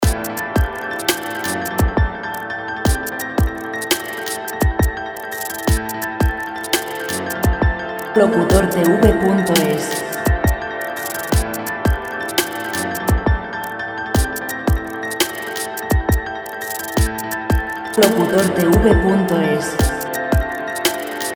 Royalty-free Chillout background music